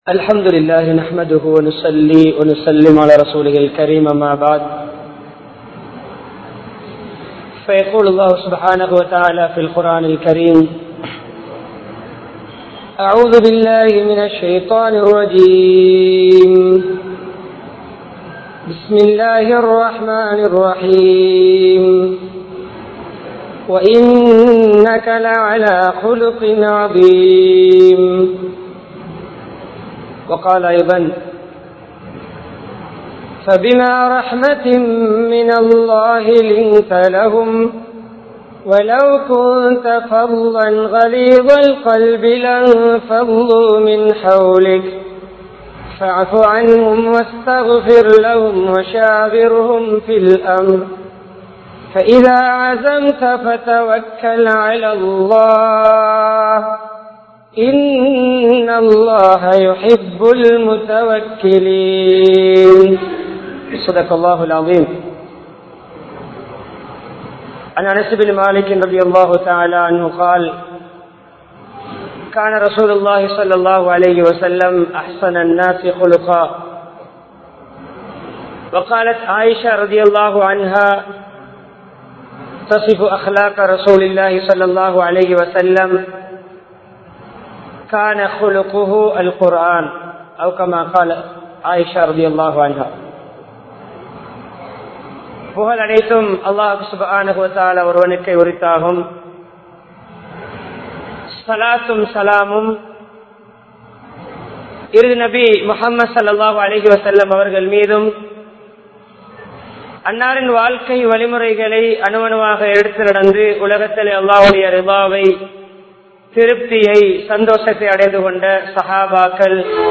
Natkunaththin Vilaivuhal (நற்குணத்தின் விளைவுகள்) | Audio Bayans | All Ceylon Muslim Youth Community | Addalaichenai